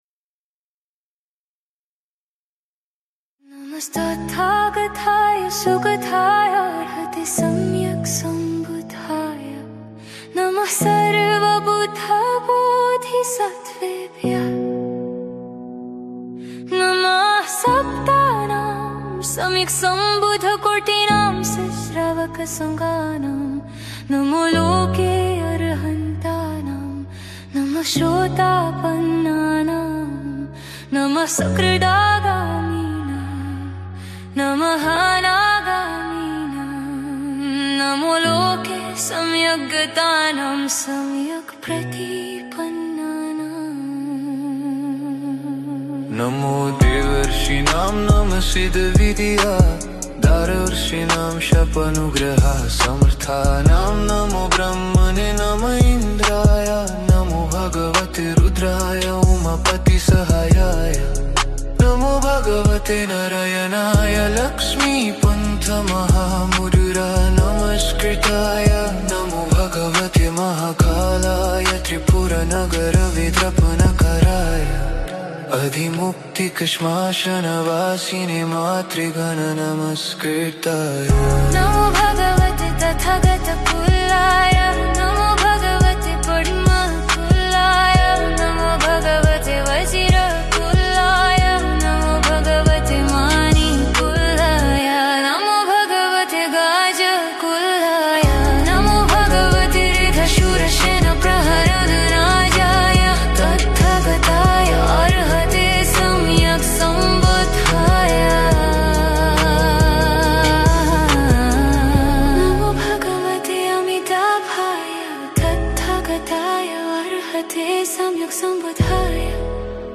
佛教音乐MP3下载